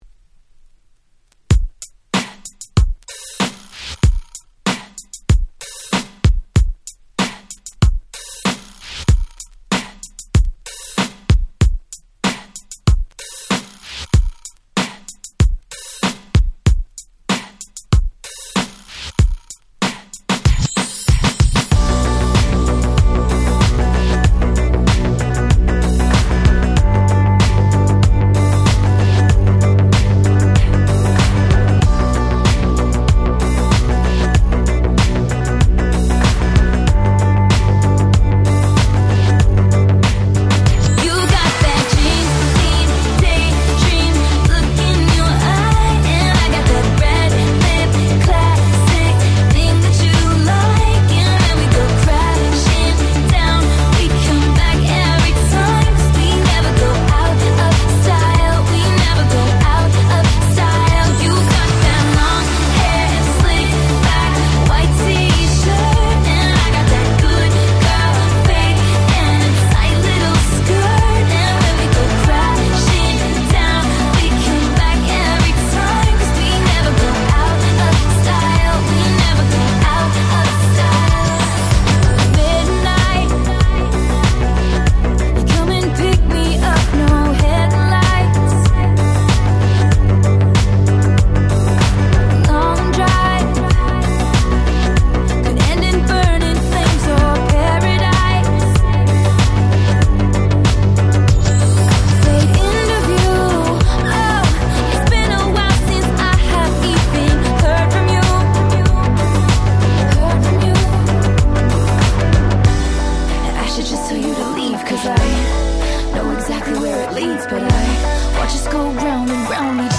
【Media】Vinyl 12'' Single (Double Pack)
自分が当時好んで使用していた曲を試聴ファイルとして録音しておきました。